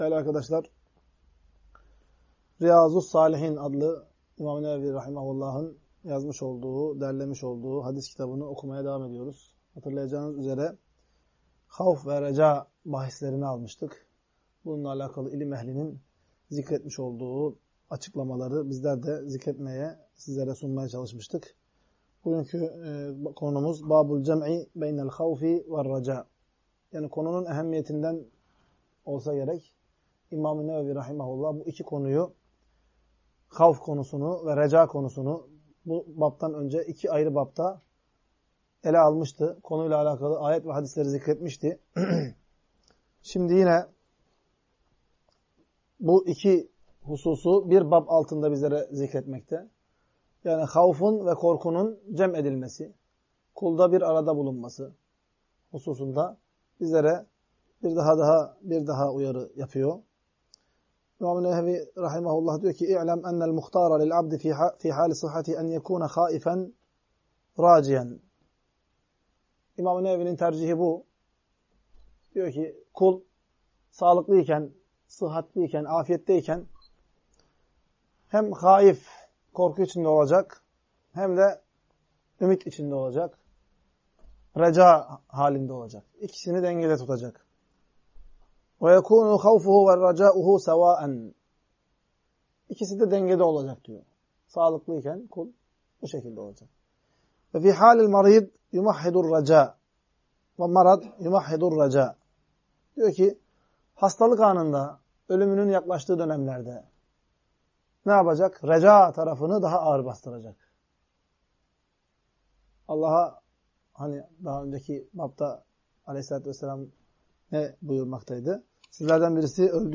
Ders.mp3